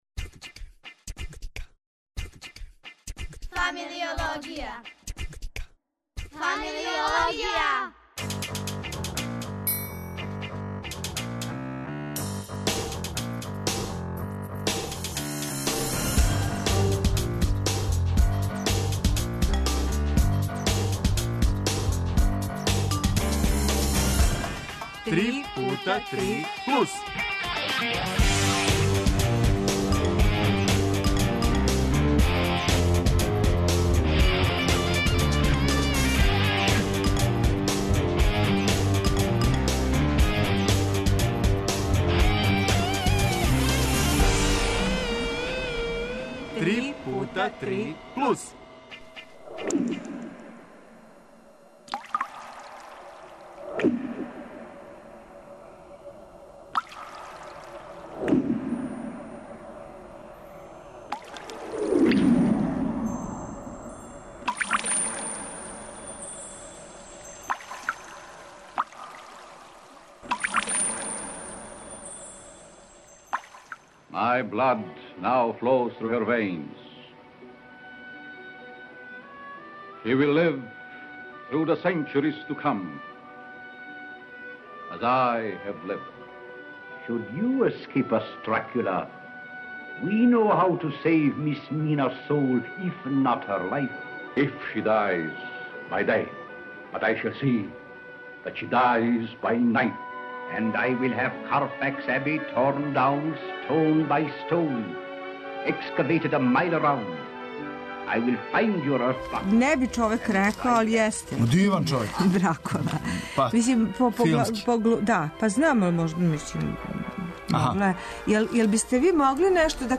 Гости нам свирају, на контрабасима...